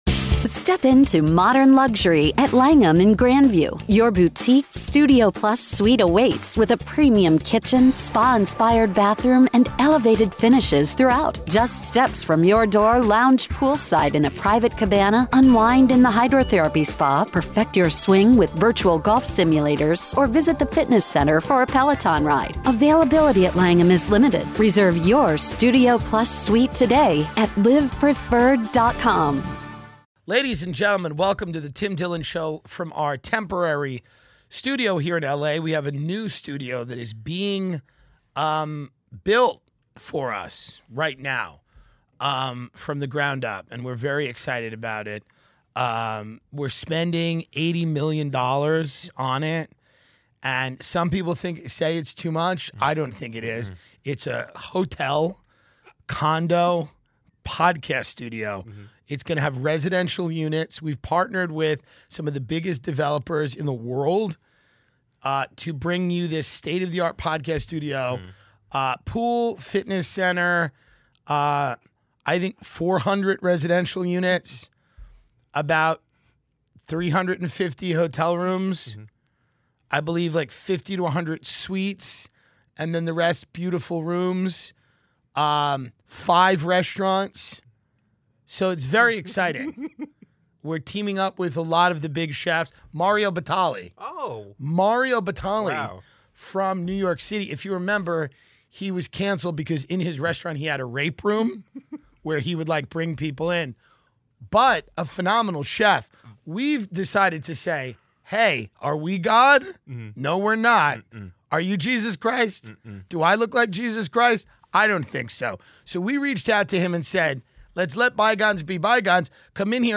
Live from sunny Los Angeles, California, in the temporary studio, Tim breaks some tragic news, gives the D'Amelio family advice to make their tv show a lot more interesting, offers the city of Malibu a word from the wise following a machete attack on a family, and gets to the bottom of why McDonald's ice cream machines mysteriously break.